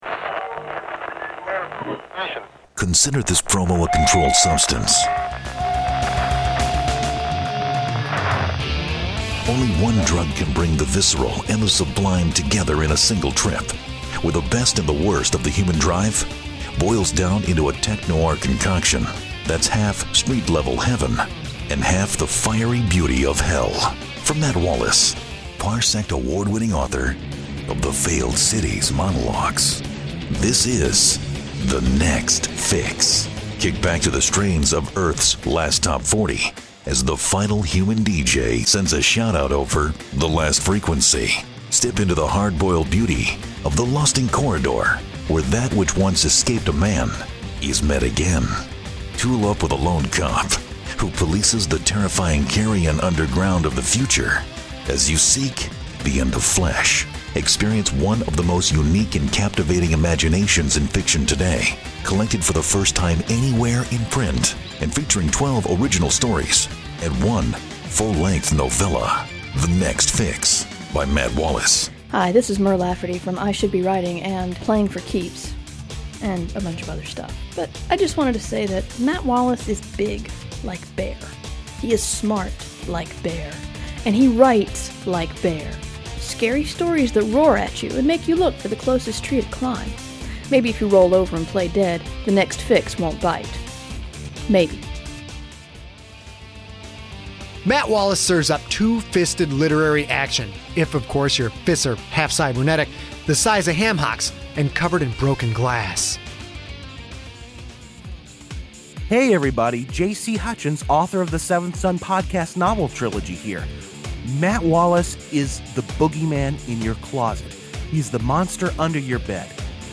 verbal testimony from some of the best authors of podcast fiction.